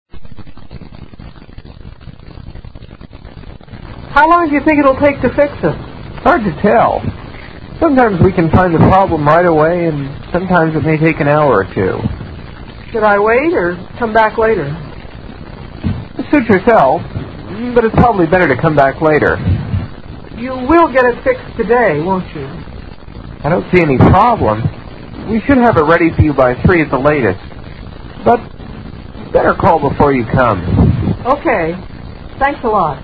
Dialogue 5
(between customer and repaimp3an)